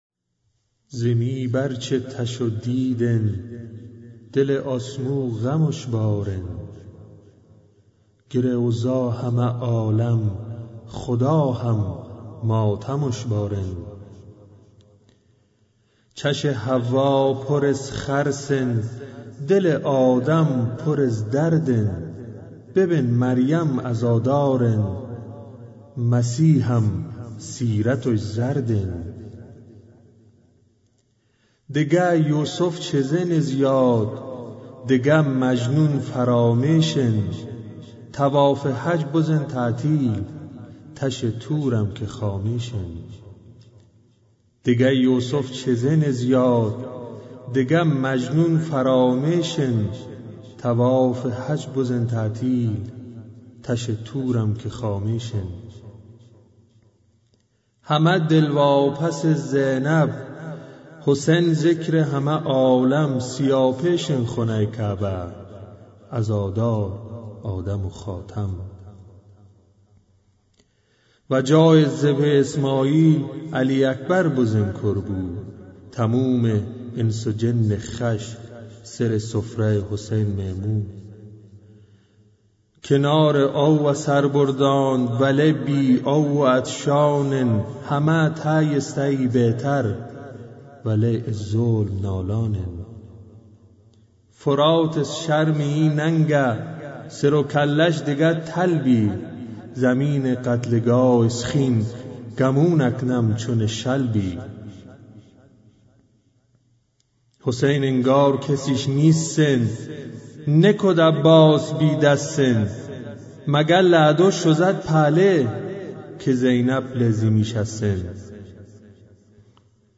نوحه گراشی